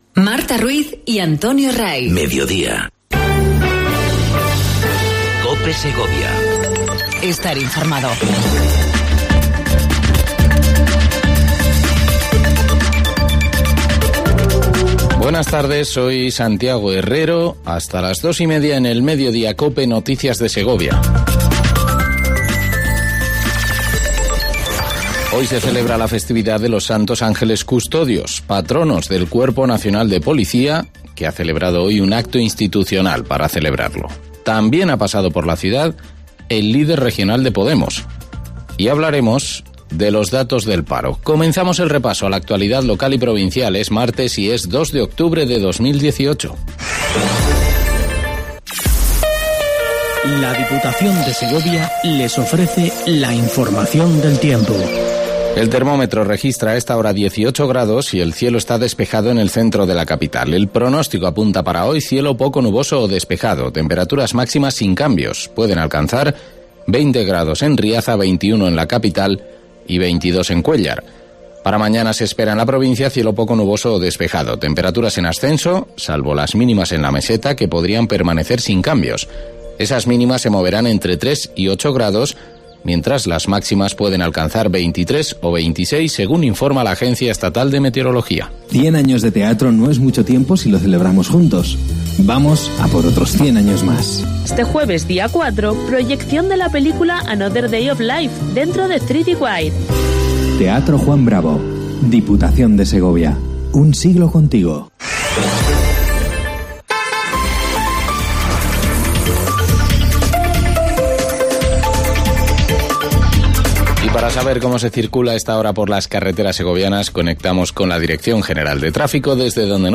INFORMATIVO MEDIODÍA COPE SEGOVIA 02 10 18